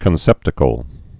(kən-sĕptə-kəl)